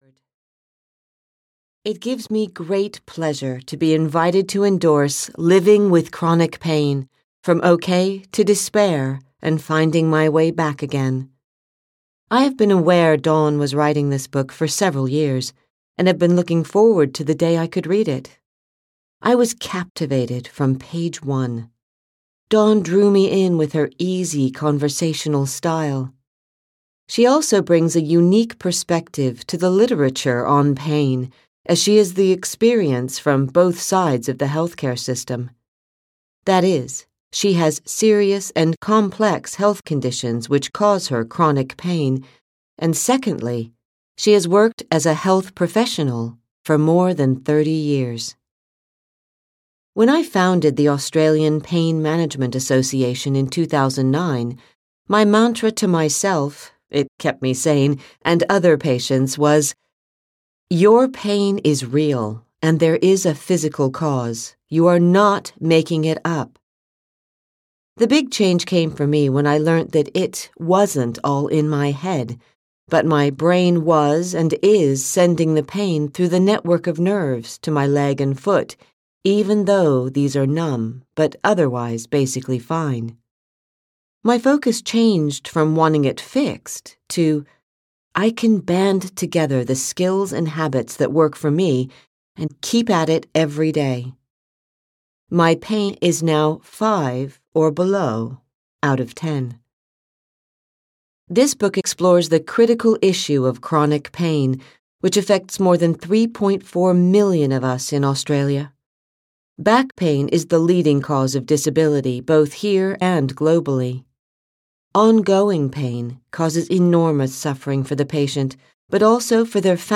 Living with Chronic Pain (EN) audiokniha
Ukázka z knihy